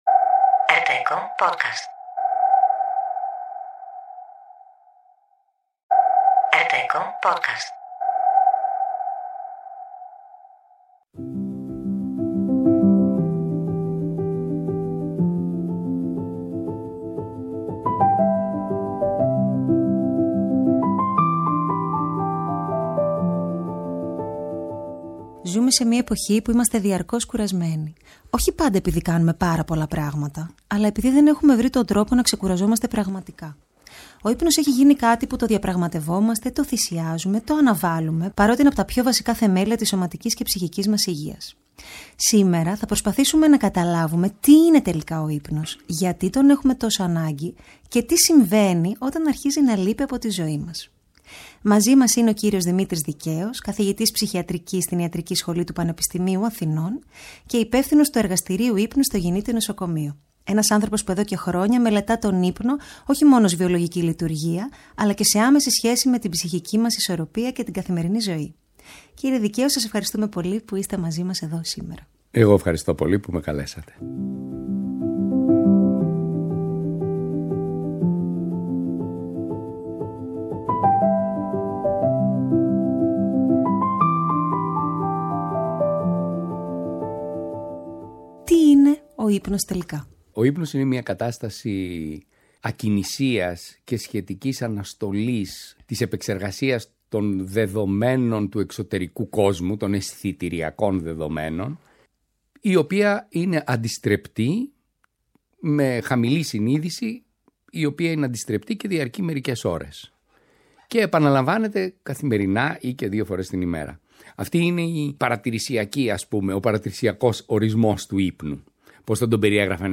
Μια συζήτηση επιστημονική, καθαρή και ανθρώπινη, που υπενθυμίζει ότι ο ύπνος δεν είναι πολυτέλεια, αλλά βασική πράξη φροντίδας.
ενώ στο τέλος κάθε επεισοδίου ακούγεται ένα νέο κομμάτι, γραμμένο από την ίδια ειδικά για τη σειρά.